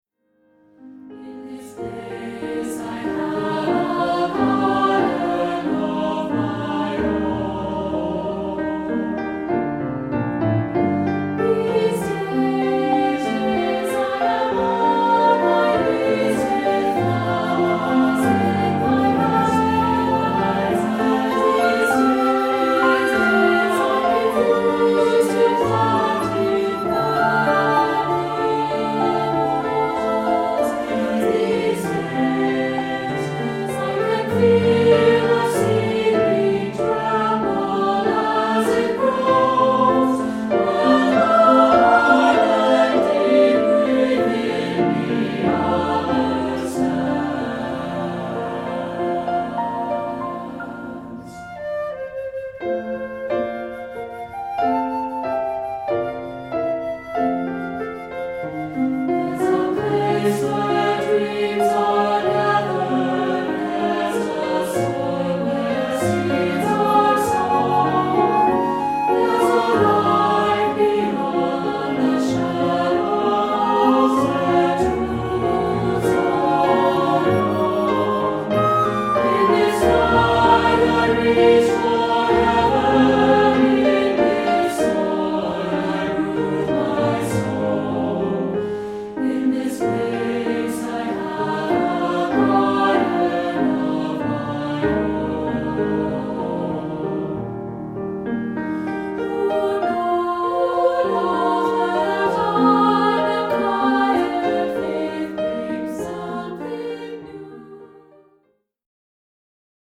SAB and Piano